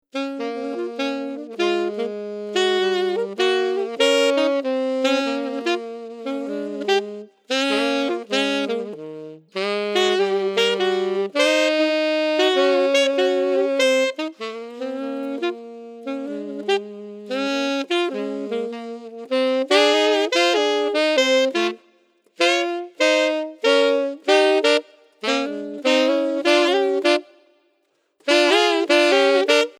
2 Saxophones